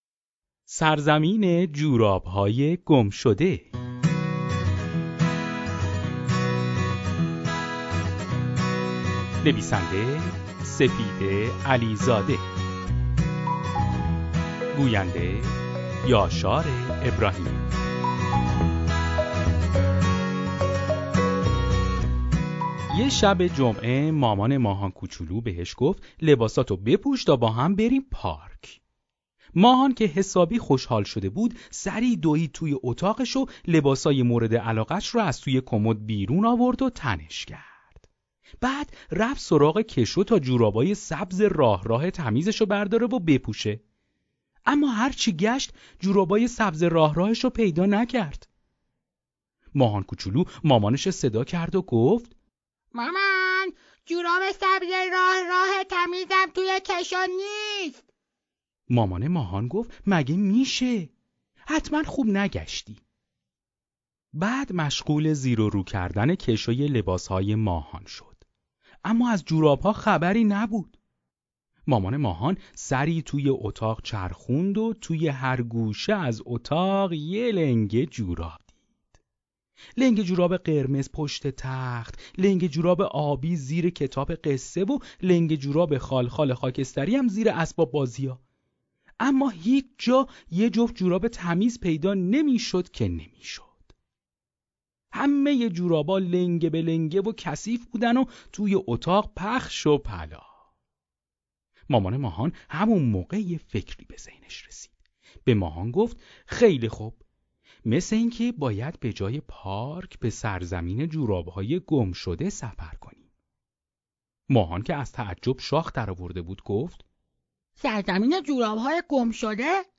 قصه صوتی کودکانه سرزمین جوراب‌های گمشده